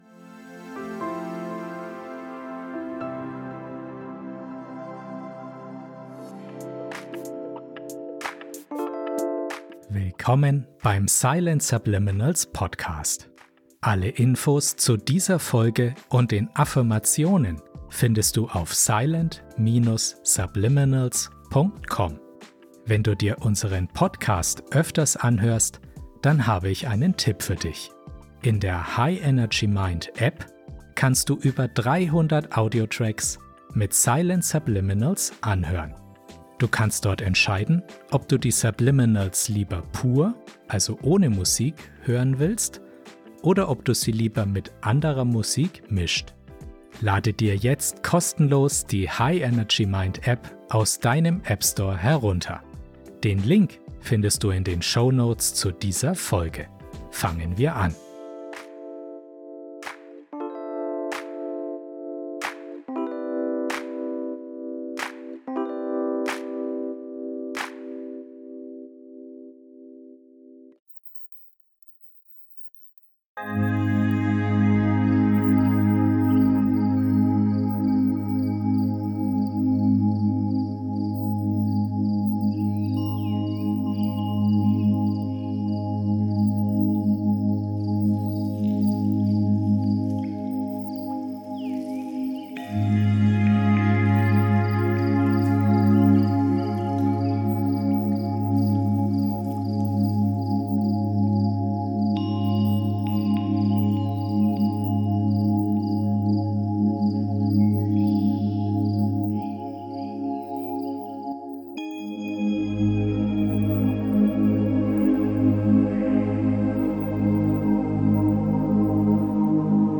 Silent Subliminals sind Audiodateien, die Botschaften auf einer Frequenz enthalten, die bewusst nicht hörbar ist, aber dennoch vom Unterbewusstsein wahrgenommen wird.